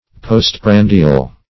Postprandial \Post*pran"di*al\, a. [Pref. post- + prandial.]